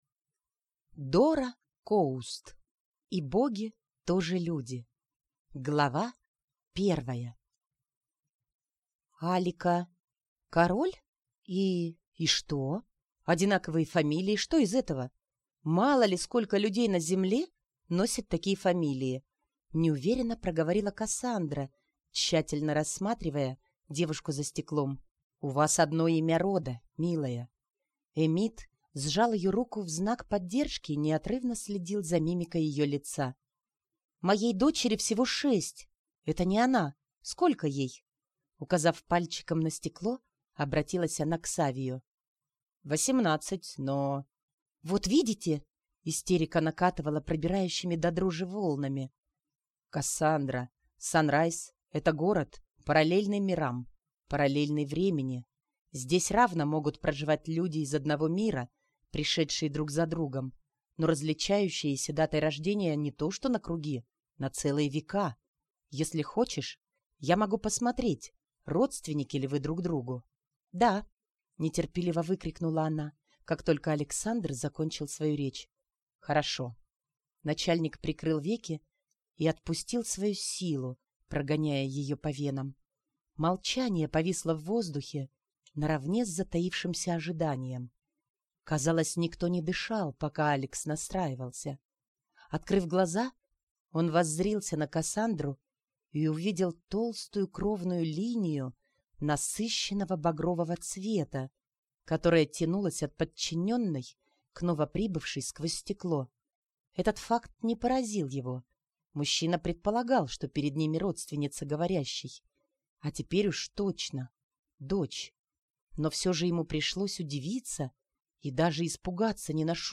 Аудиокнига И Боги тоже люди | Библиотека аудиокниг